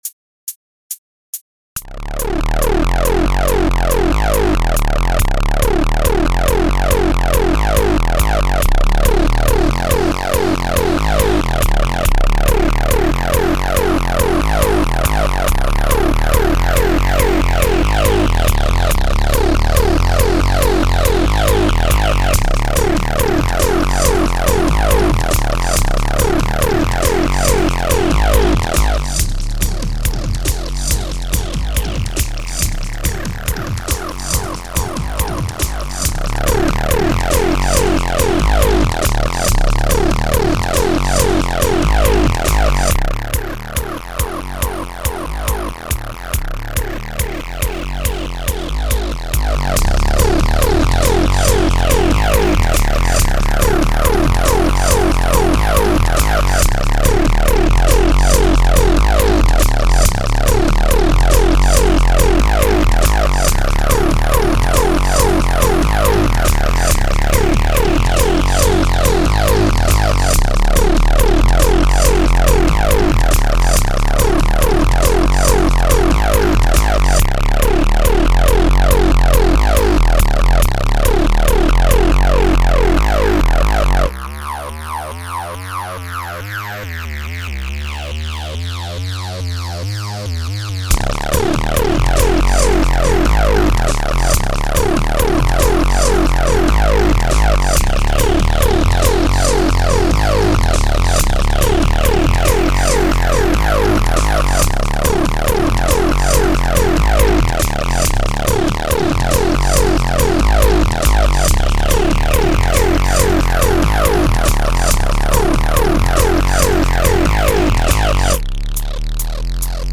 Synth music